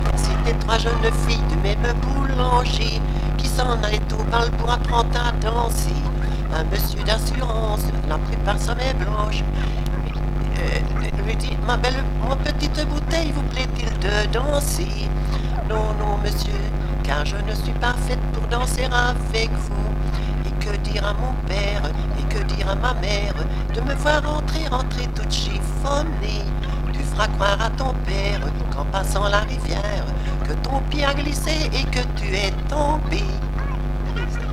Genre : chant
Type : chanson narrative ou de divertissement
Lieu d'enregistrement : Hollogne-aux-Pierres
Support : bande magnétique
Sur l'air du tradéridéra. Son endommagé.